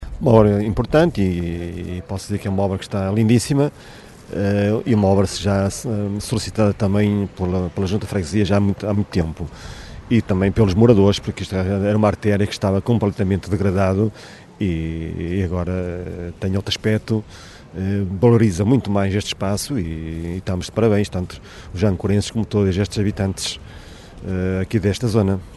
Presente na cerimónia, o presidente da Junta de Freguesia de Vila Praia de Âncora, Carlos Castro, considerou que a obra estava “lindíssima”.